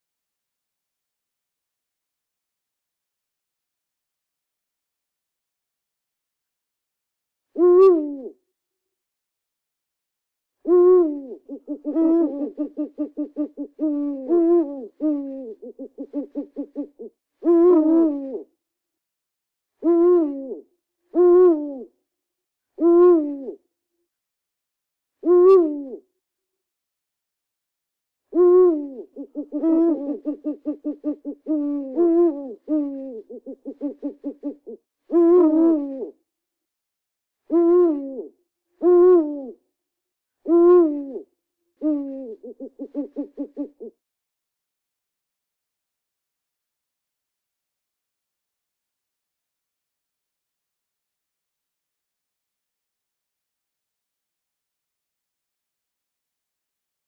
Sonido del buho real.mp3